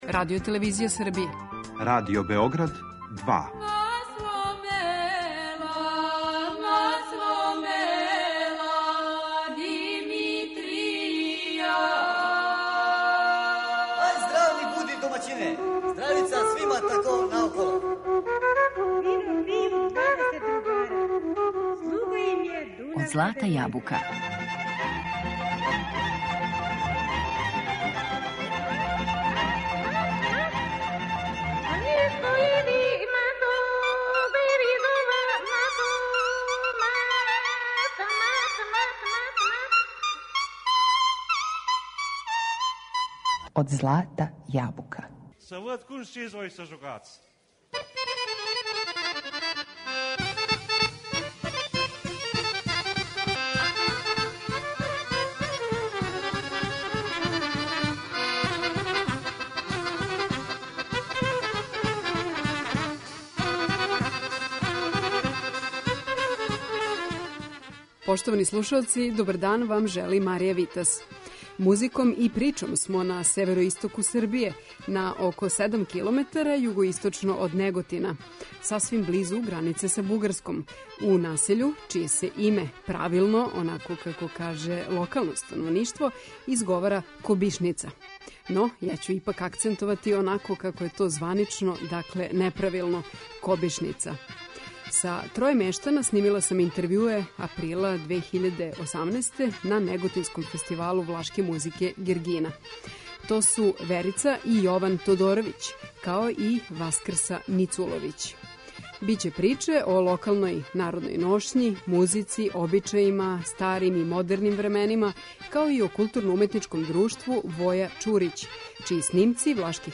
Кобишница - прича и музика